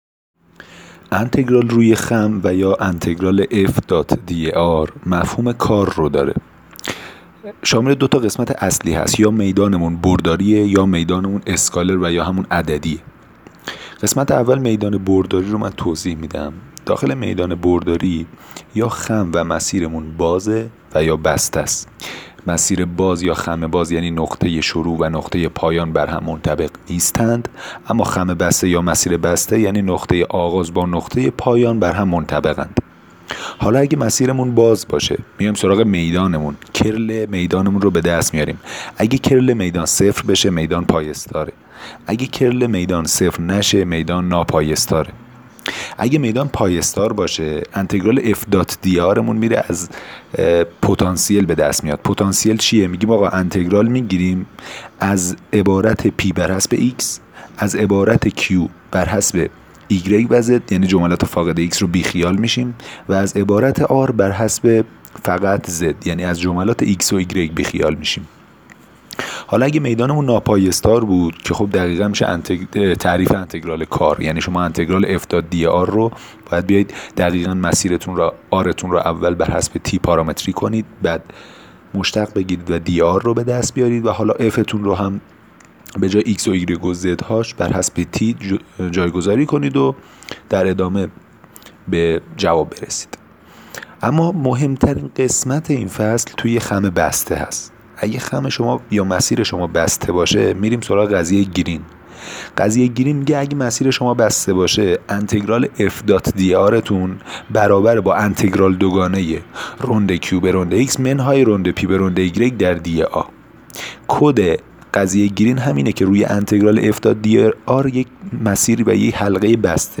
فایل صوتی تدریس انتگرال روی خم